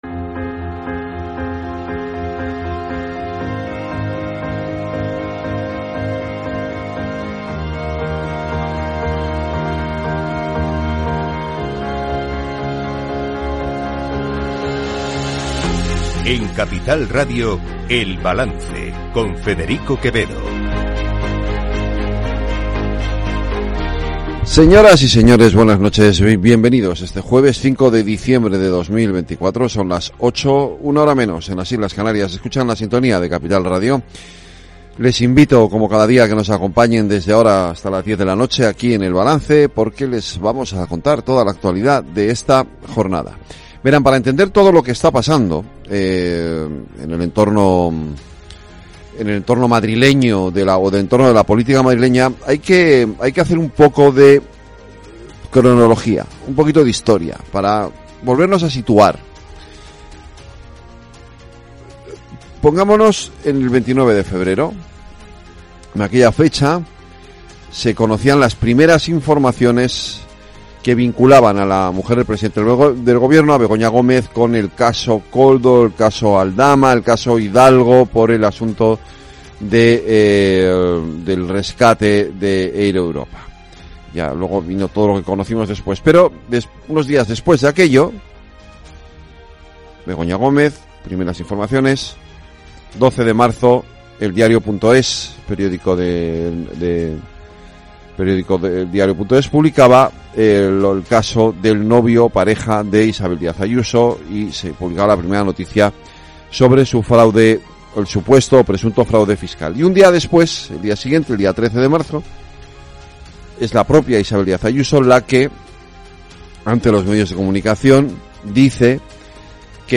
El Balance es el programa informativo nocturno de Capital Radio, una manera distinta, sosegada y reflexiva de analizar la actualidad política y económica